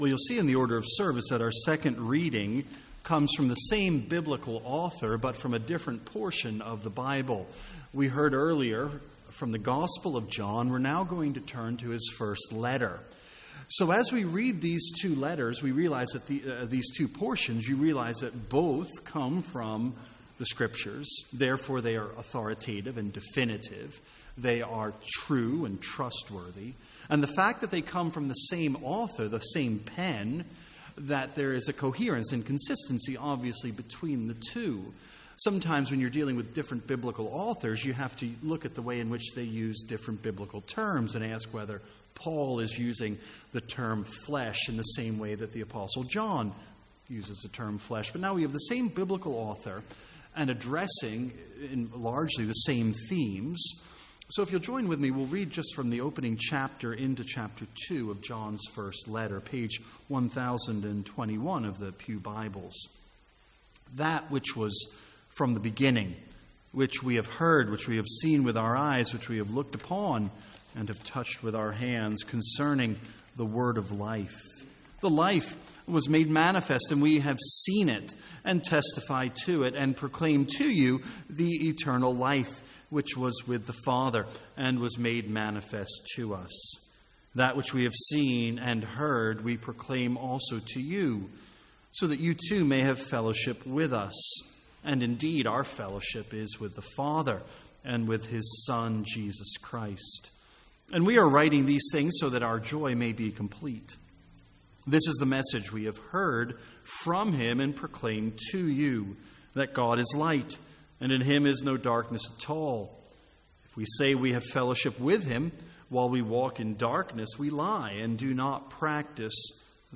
Sermons | St Andrews Free Church
From the Sunday evening series on the Five Points of Calvinism.